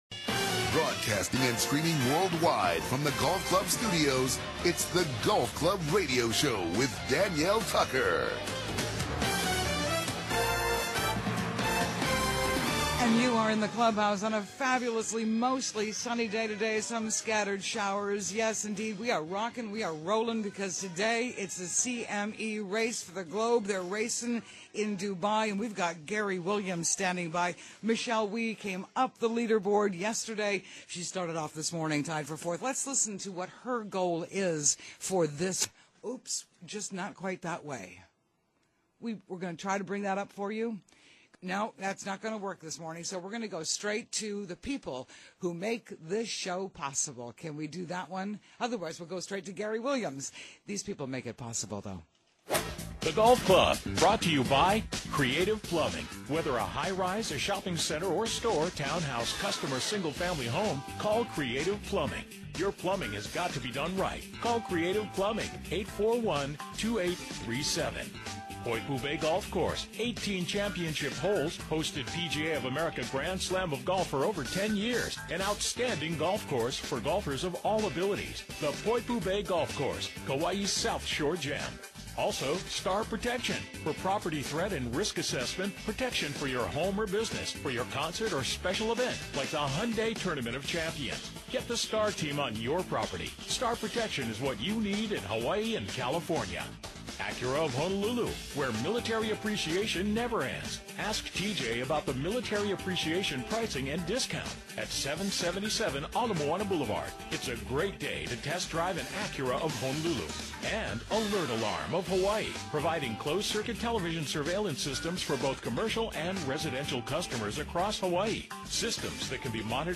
The Golf Club Radio Show� broadcasting state-wide talking to Hawaii's Golf Pros and across America sports shrinks, authors, mental coaches and PGA broadcasters.